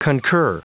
Prononciation du mot concur en anglais (fichier audio)
Prononciation du mot : concur